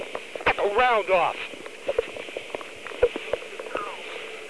Electronic Voice Phenomena